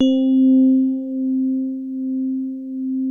E-PIANO 1
TINE SOFT C3.wav